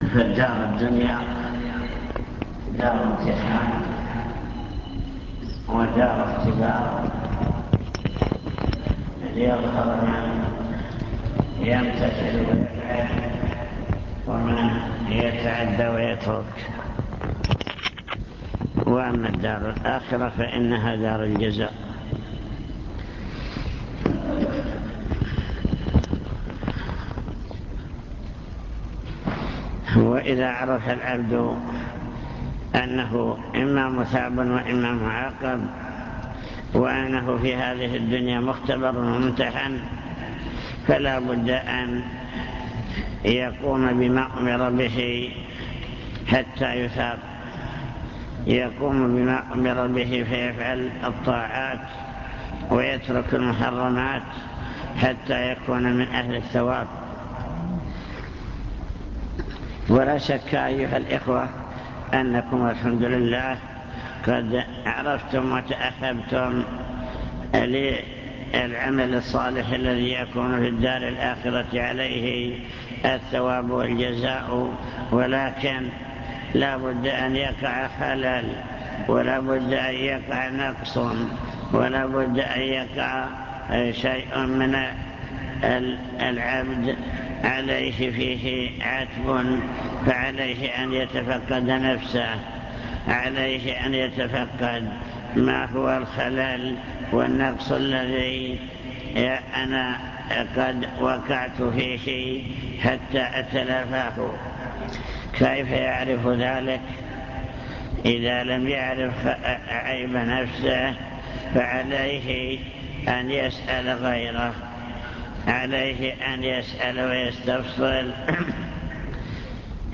المكتبة الصوتية  تسجيلات - لقاءات  عوامل صلاح المجتمع (لقاء مفتوح)